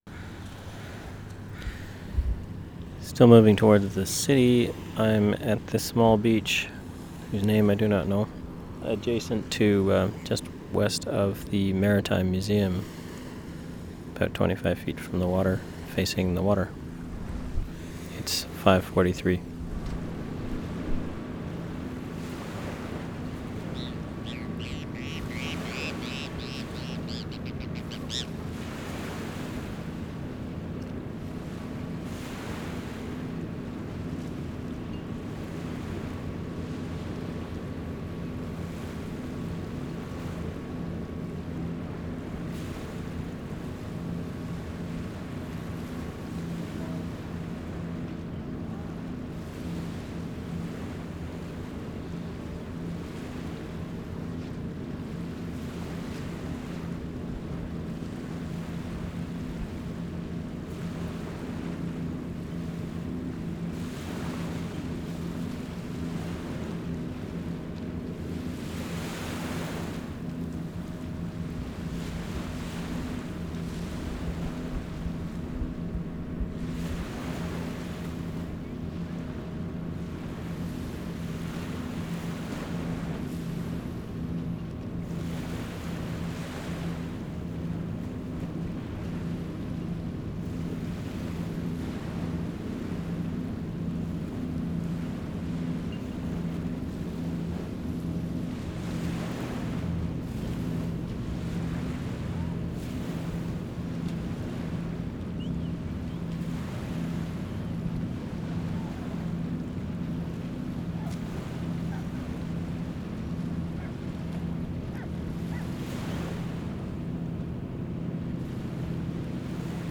3. Bloedel Conservatory, interior 5:12
3. ID and intro, fountain, people talking, many birds, ventilation, much hiss from fountain and ventilation